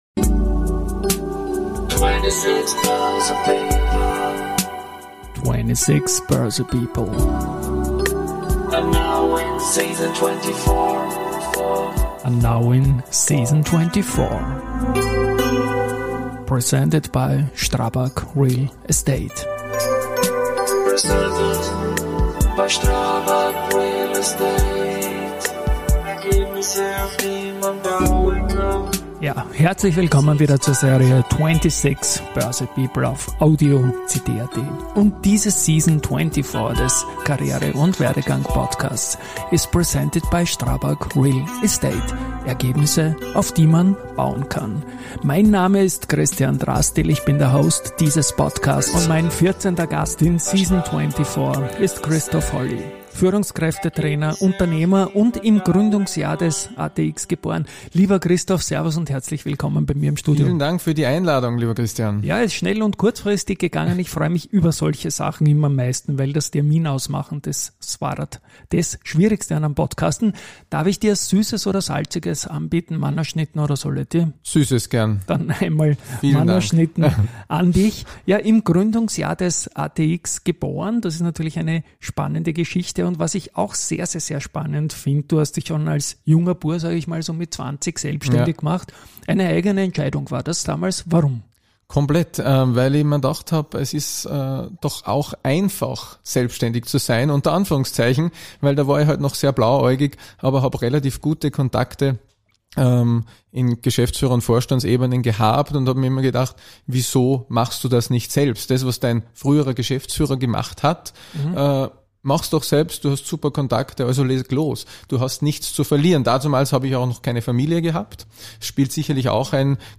Es handelt sich dabei um typische Personality- und Werdegang-Gespräche.